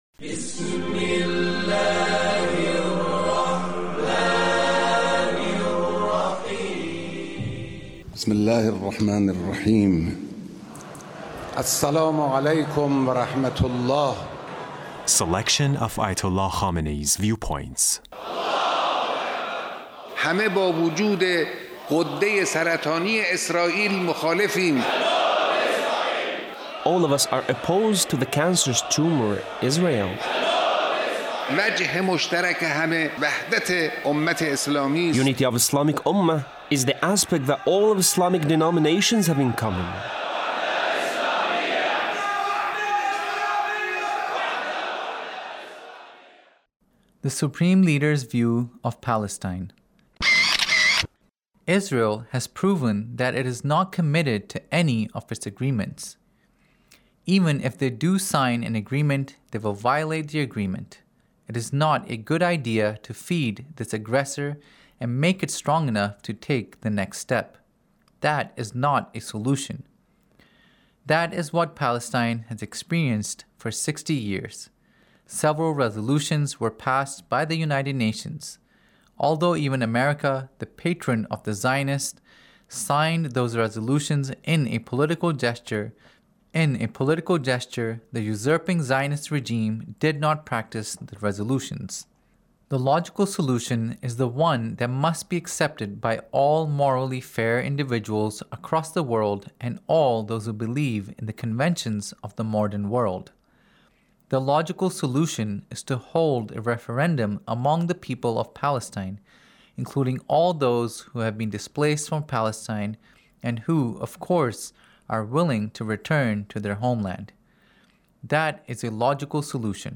Leader's Speech (1870)